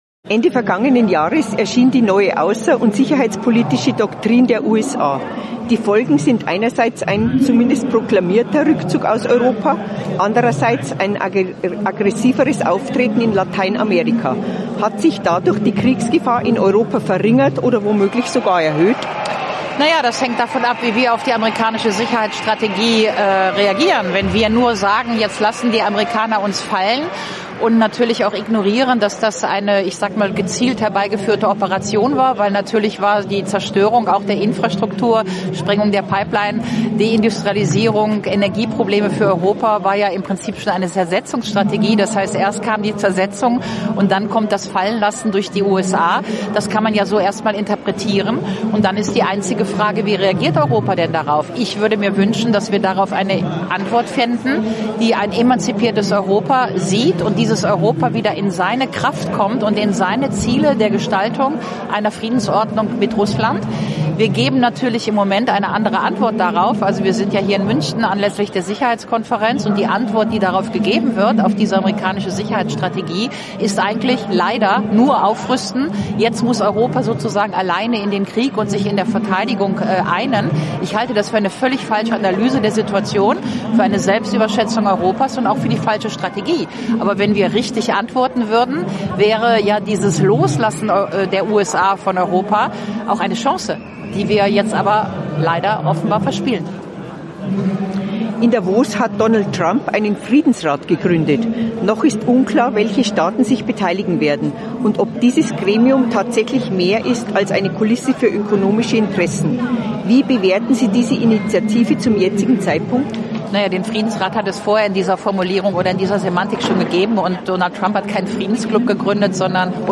Klare Worte zu Trumps Politik gegen die EU findet die Politikwissenschaftlerin Ulrike Guérot im Interview mit AUF1